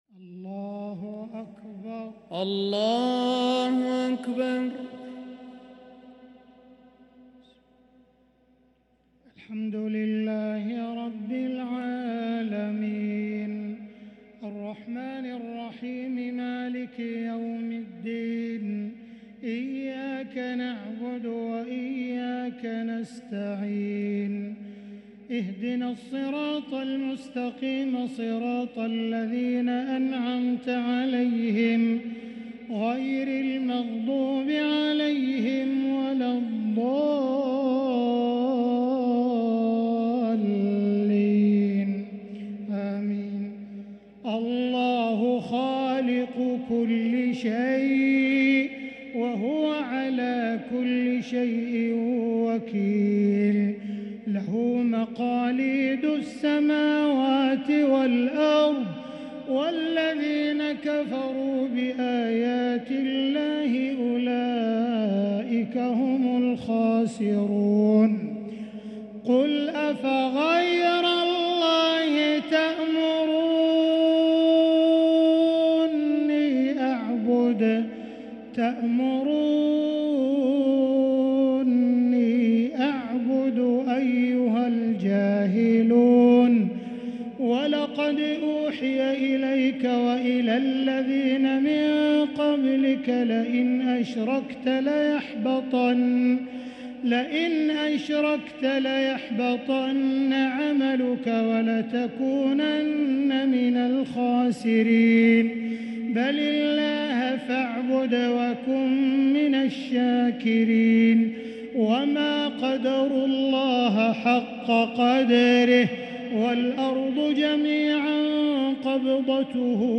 تهجد ليلة 25 رمضان 1444هـ من سورتي الزمر (62-75) و غافر (1-22) | Tahajjud 25 st night Ramadan 1444H Surah Az-Zumar and Ghafir > تراويح الحرم المكي عام 1444 🕋 > التراويح - تلاوات الحرمين